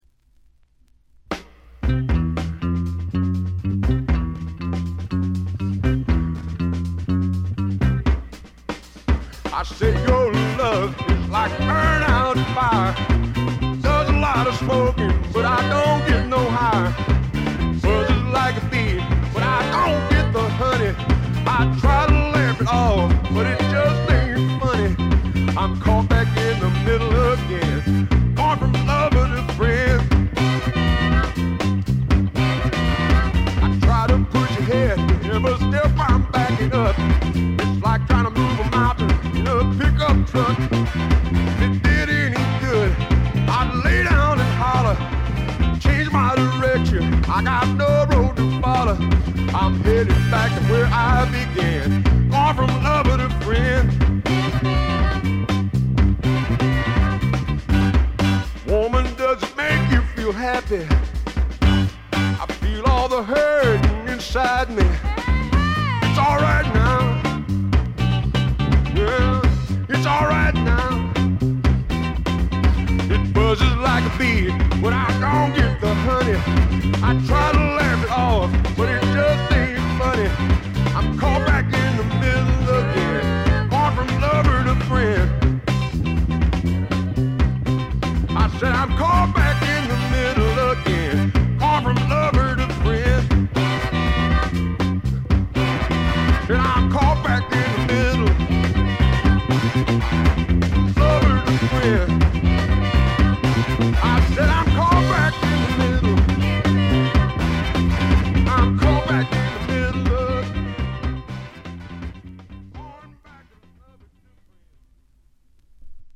テキサス産スワンプポップの名作。
試聴曲は現品からの取り込み音源です。